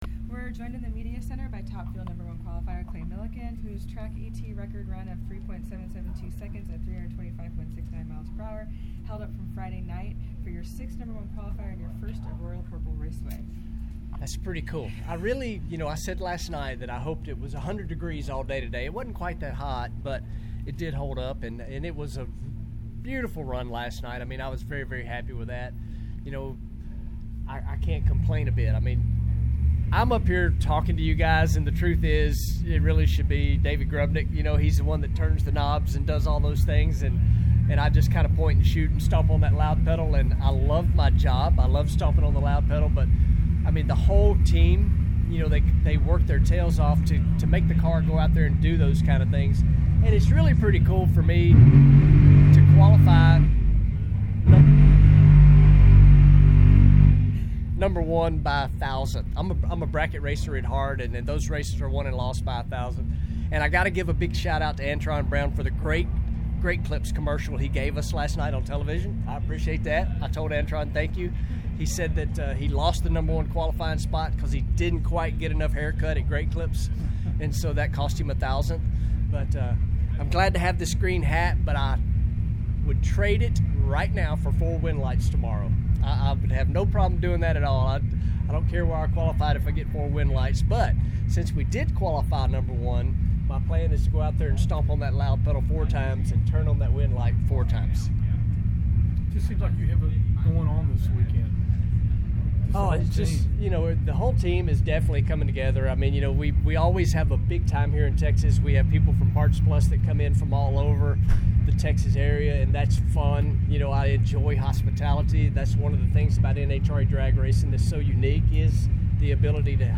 Interviews from the media center: